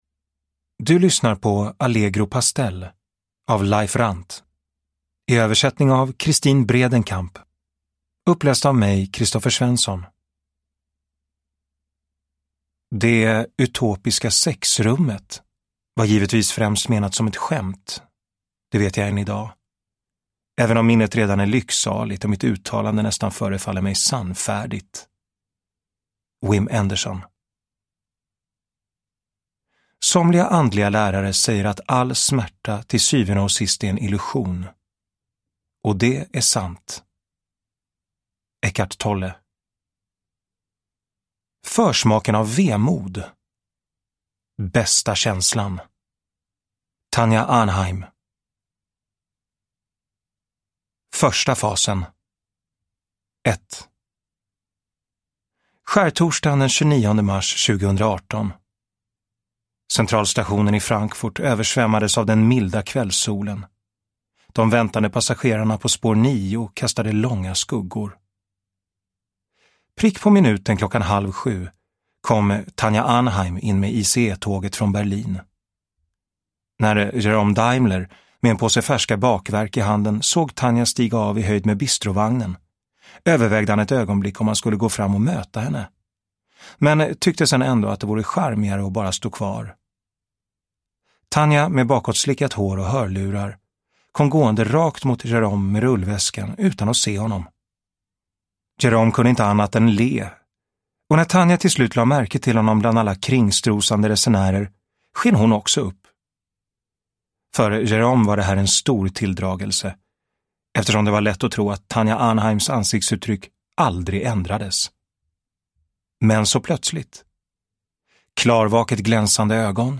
Allegro pastell – Ljudbok – Laddas ner